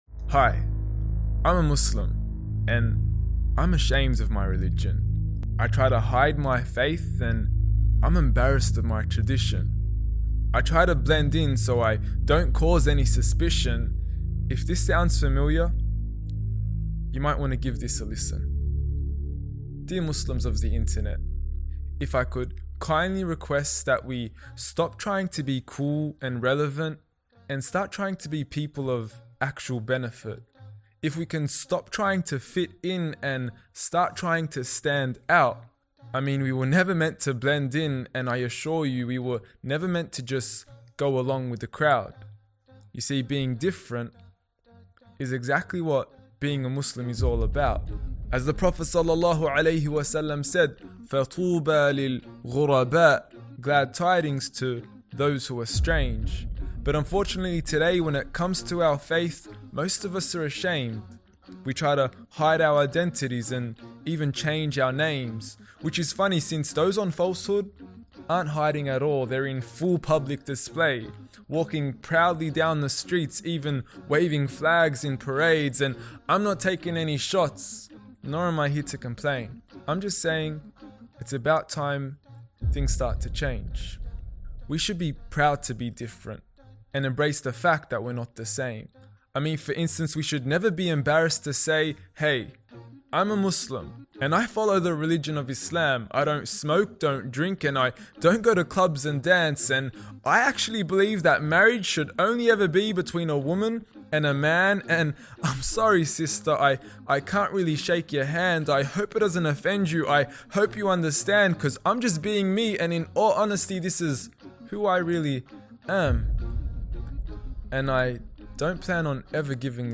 Ashamed to be Muslim ｜ Spoken Word